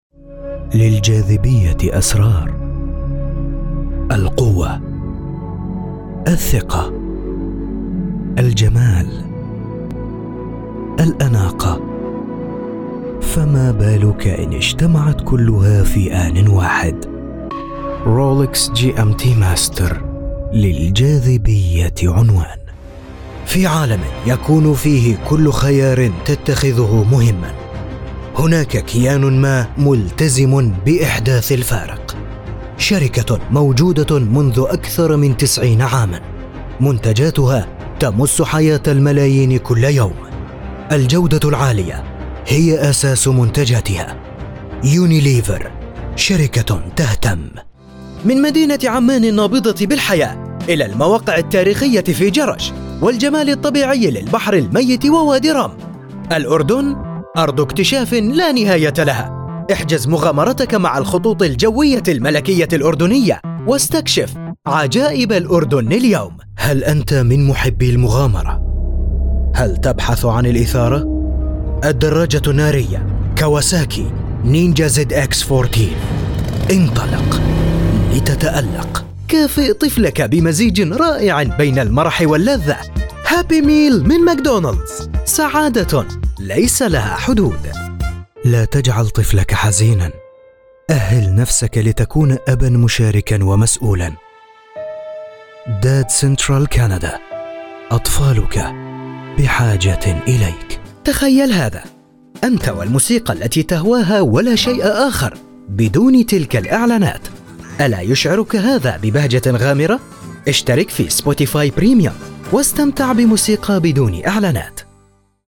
Male
A warm, confident Arabic voice with a clear, polished tone that instantly builds trust. The delivery is natural, steady, and engaging, balancing professionalism with approachability.
Arabic Commercial Voice